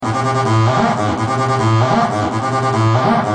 Sons et loops gratuits de TB303 Roland Bassline
Basse tb303 - 46